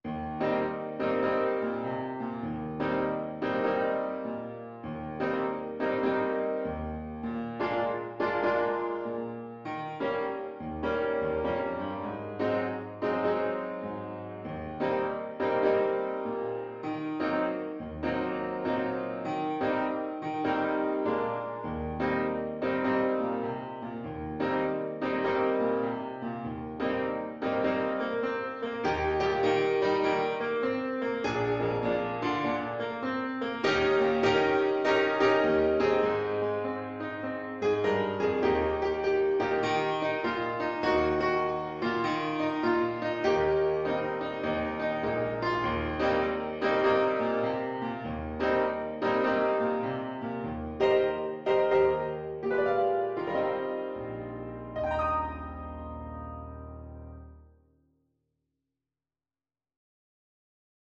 Play (or use space bar on your keyboard) Pause Music Playalong - Piano Accompaniment Playalong Band Accompaniment not yet available reset tempo print settings full screen
Moderate swing
Eb major (Sounding Pitch) (View more Eb major Music for Trombone )